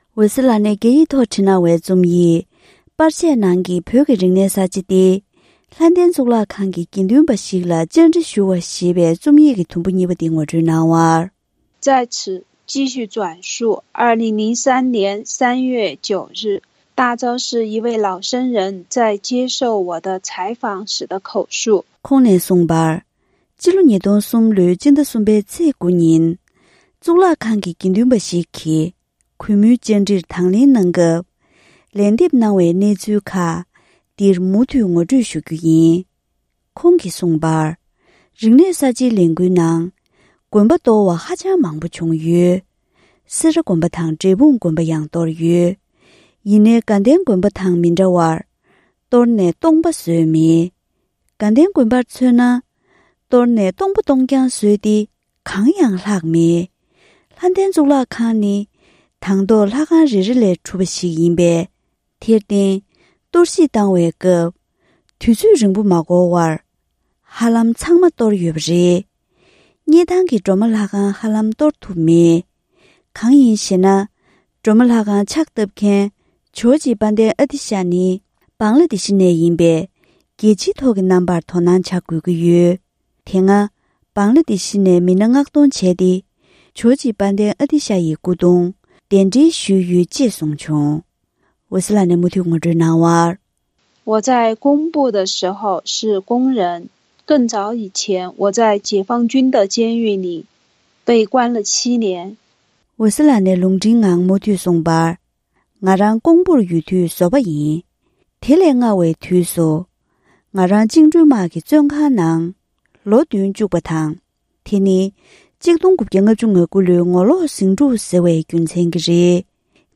གཙུག་ལག་ཁང་གི་དགེ་འདུན་པ་ཞིག་ལ་བཅར་འདྲི་ཞུས་པ།